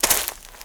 High Quality Footsteps
STEPS Leaves, Walk 09.wav